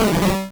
Cri de Chétiflor dans Pokémon Rouge et Bleu.